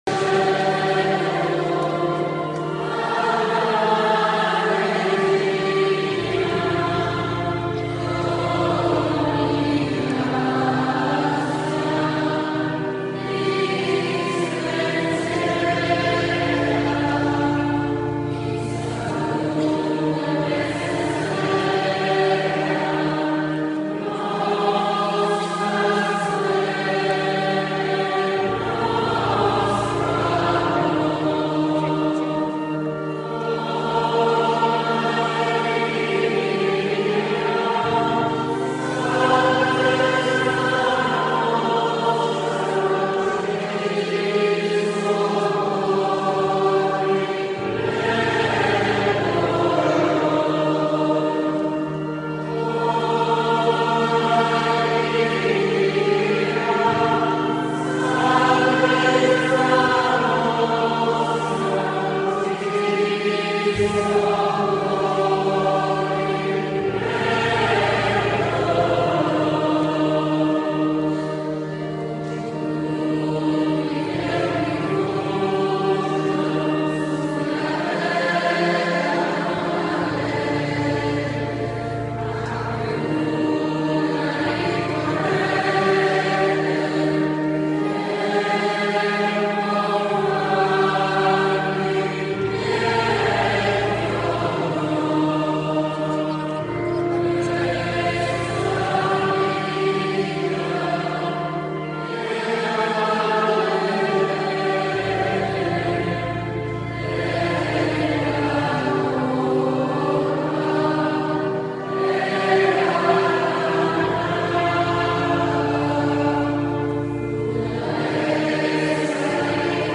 Inno-Maria-SS-Salute-degli-Infermi.mp3